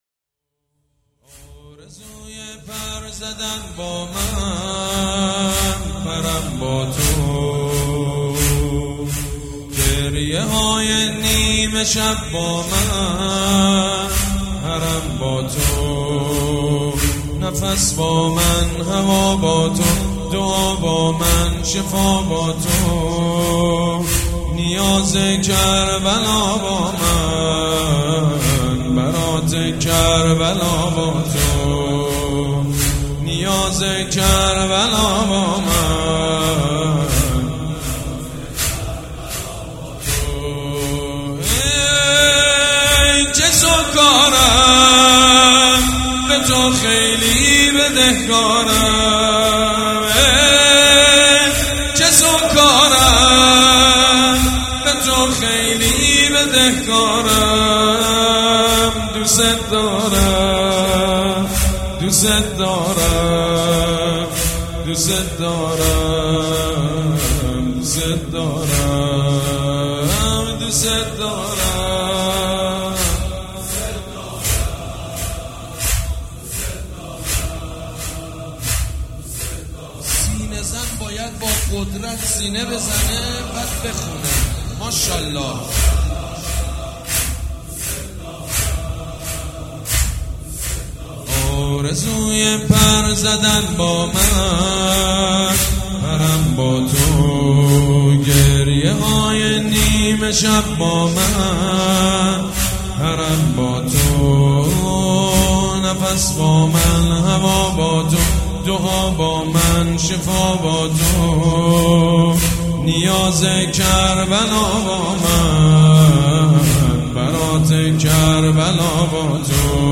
مداح
حاج سید مجید بنی فاطمه
وفات حضرت زینب (س)